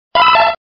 Archivo:Grito de Seadra.ogg